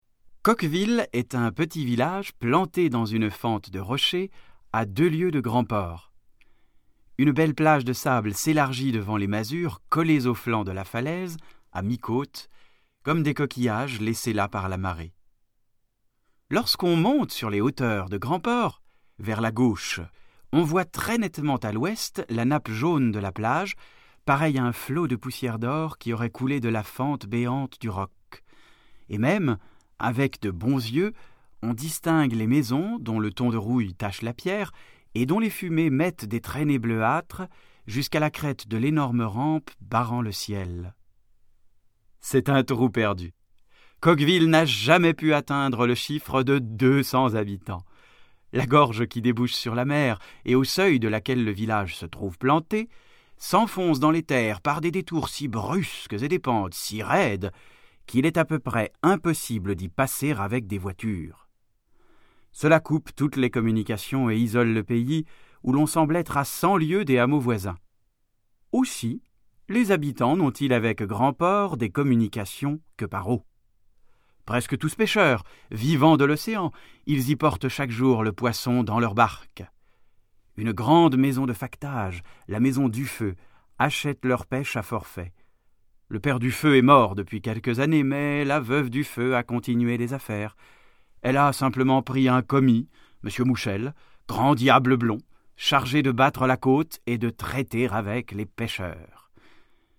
Click for an excerpt - La Fête à Coqueville de Emile Zola